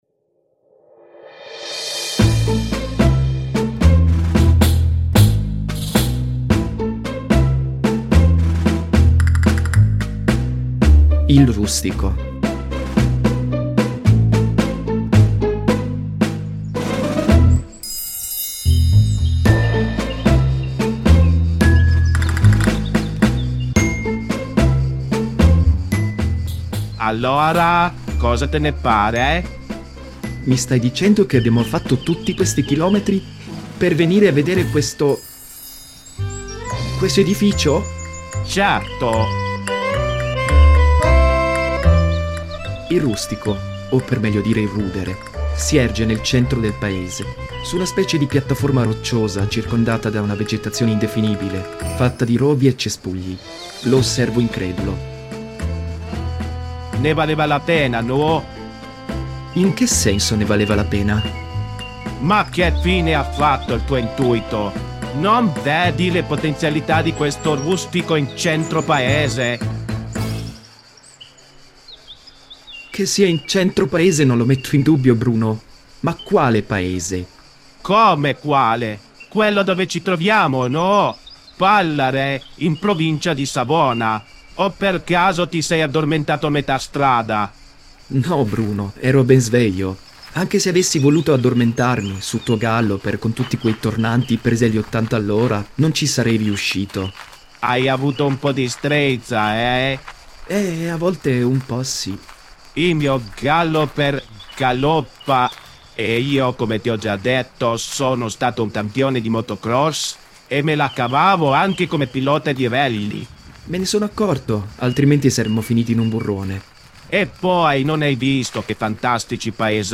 Nel corso dell'episodio si può ascoltare un brano dalla "Habanera" della Carmen di Bizet.
During the episode you can listen to a piece from the "Habanera" of Bizet's Carmen.